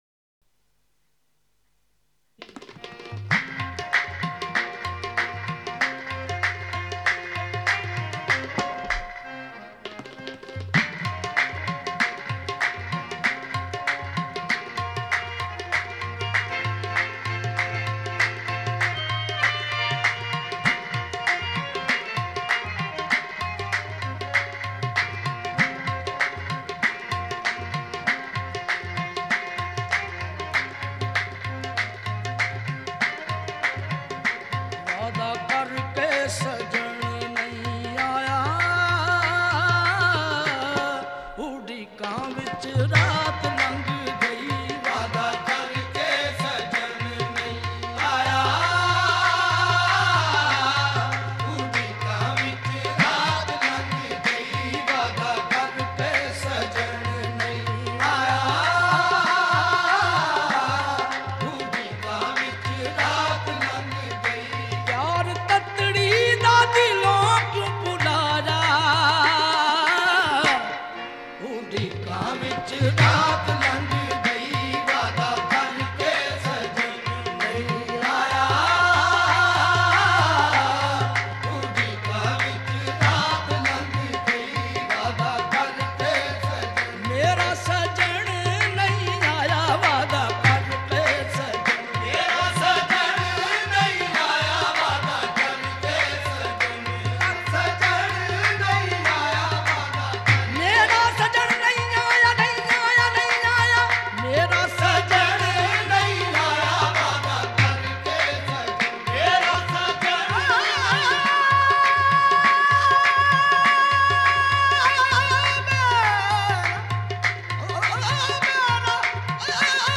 Kalaam/Poetry
Sufi Songs